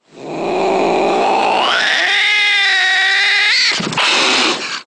Divergent / mods / Soundscape Overhaul / gamedata / sounds / monsters / cat / hit_1.ogg